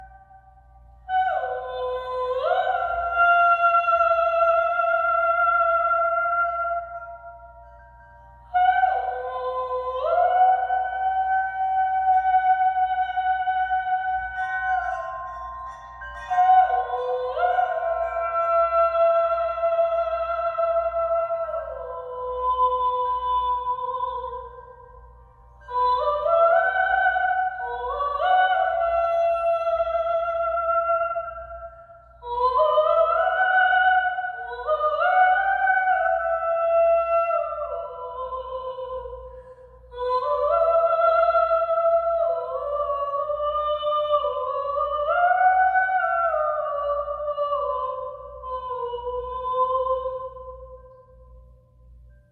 Carillons et Voix                    Durée 06:23